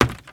STEPS Wood, Creaky, Run 01.wav